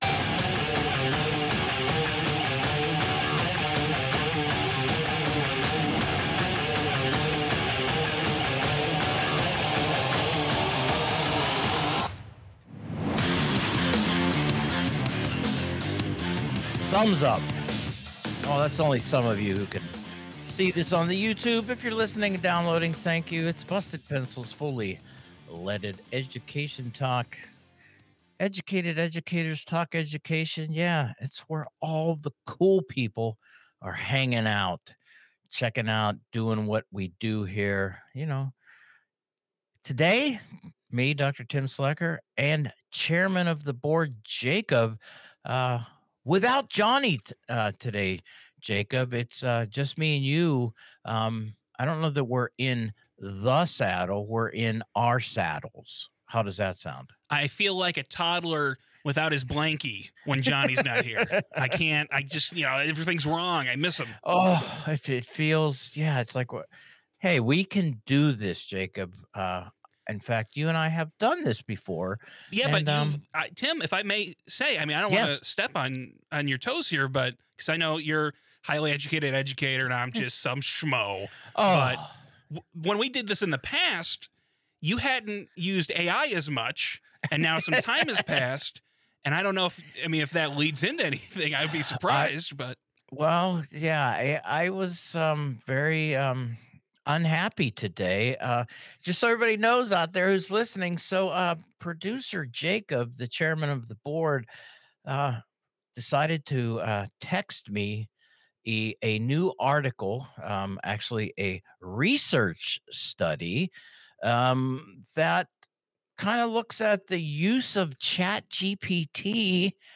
a quiet, contemplative conversation